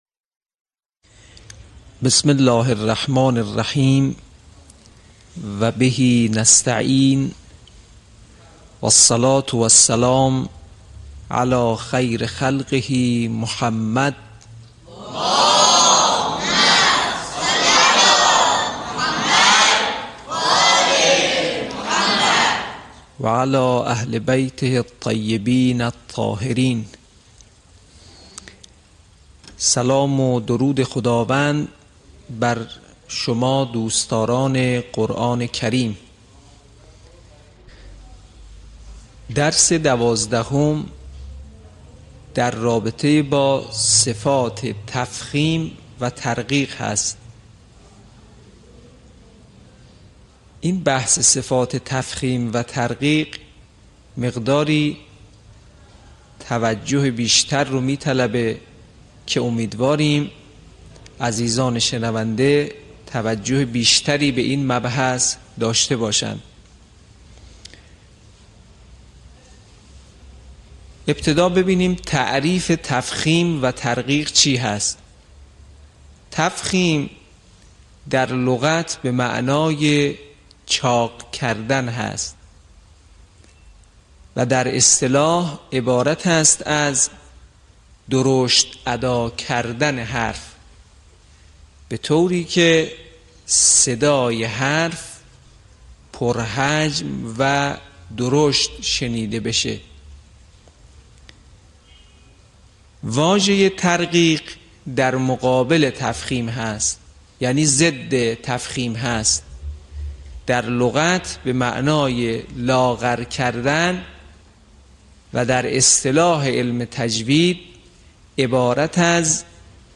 صوت | آموزش تفخیم و ترقیق